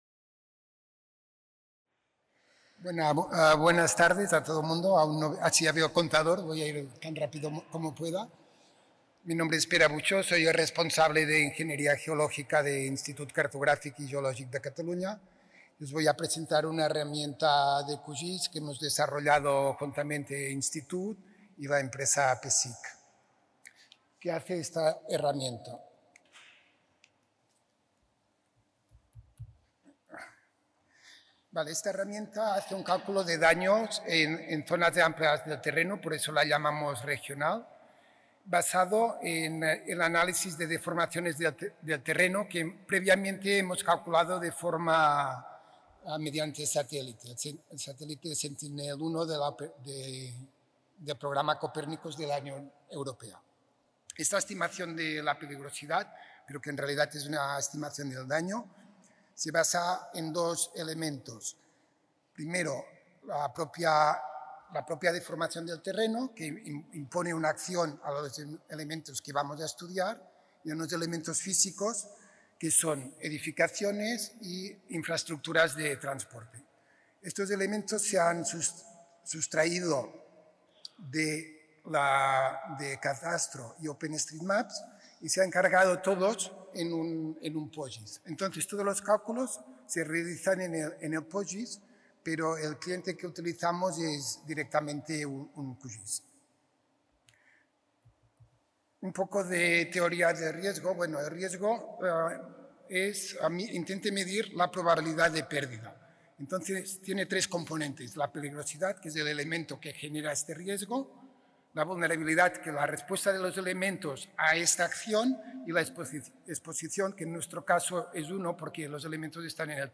En aquesta breu xerrada s'explica l'eina RQRAI desenvolupada per l'ICGC amb el programari QGIS per l'anàlisi quanitatiu del risc (QRA) motivat per moviments de terreny, fent servir dades regionals d'interferometria radar